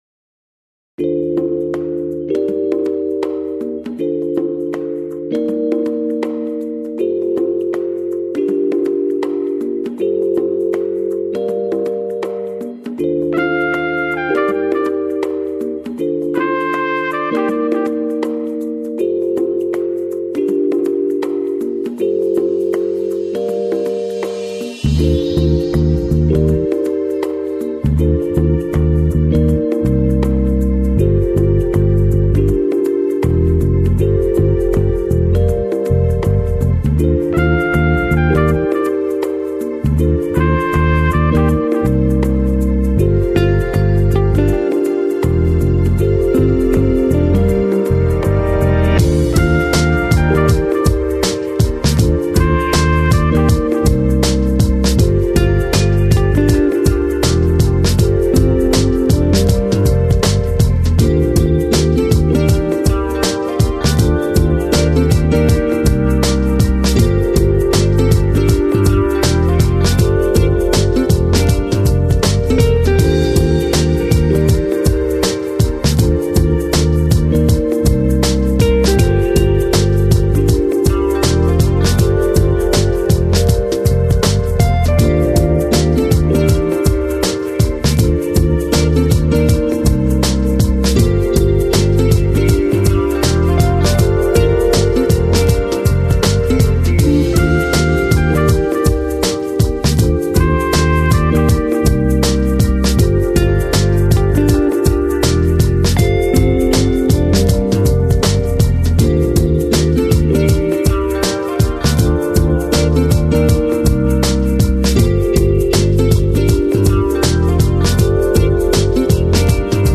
Lounge, Chill Out, Downtempo